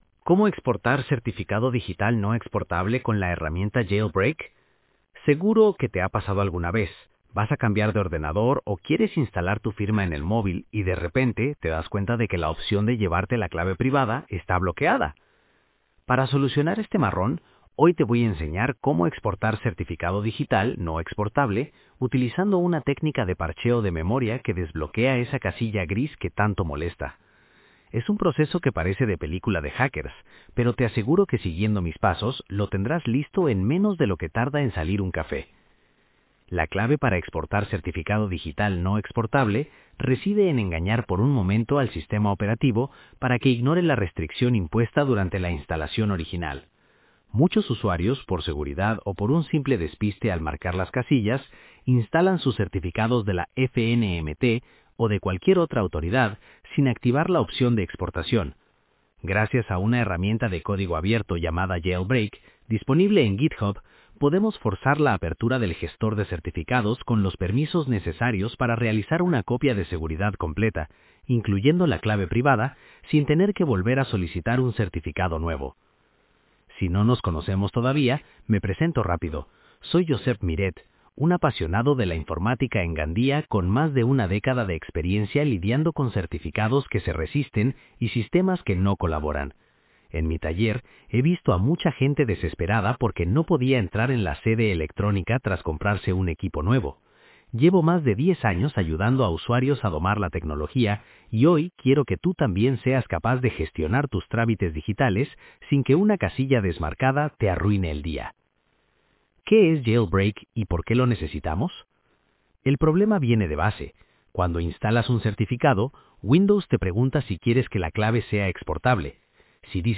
Dale al play para escuchar el artículo Exportar certificado digital no exportable